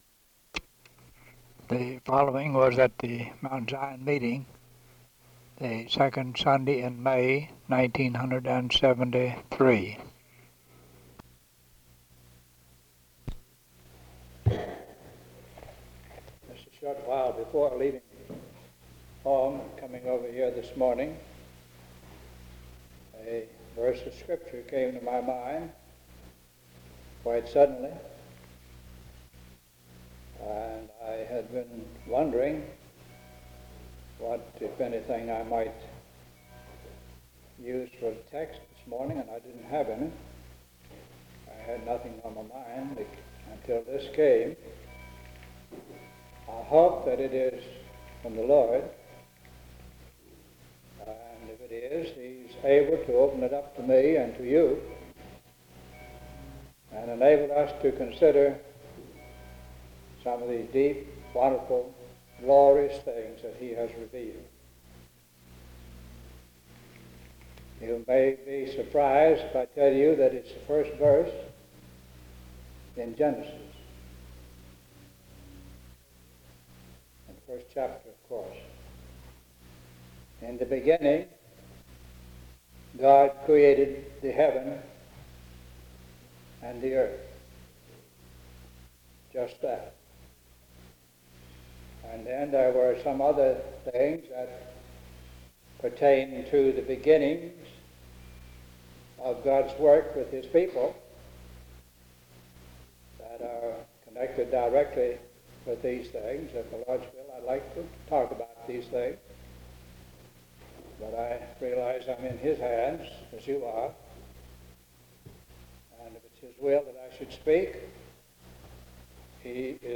Genesis 1:1, Sermon on God's creation of the world, (The introduction on the tape claims that this recording is from Mt. Zion on the second Sunday in May, but the sermon fits the title on the box.)